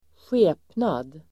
Uttal: [²sj'e:pnad]